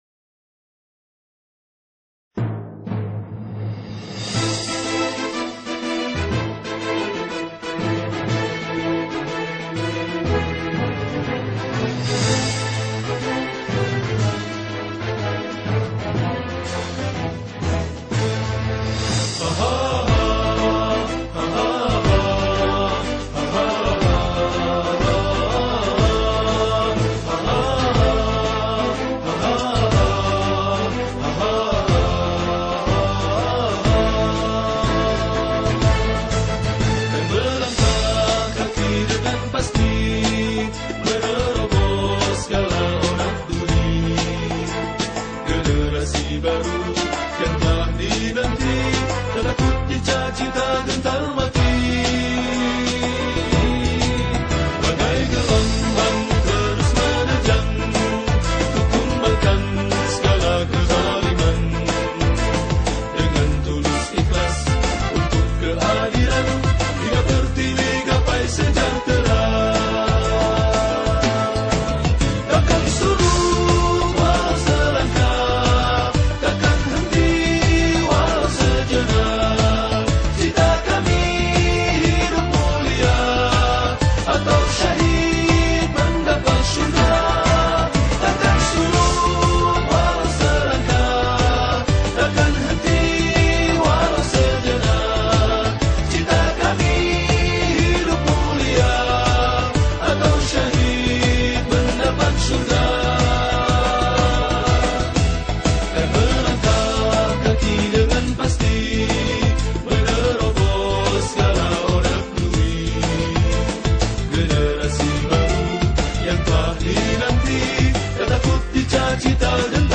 Nasyid Songs
Skor Angklung